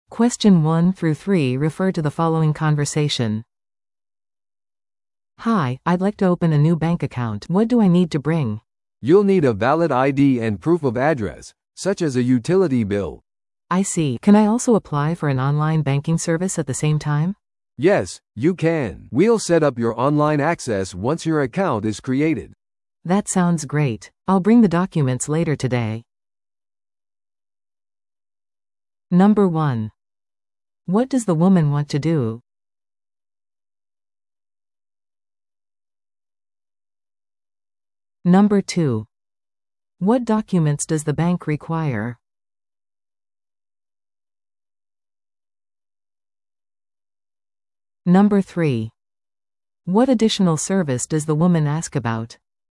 No.1. What does the woman want to do?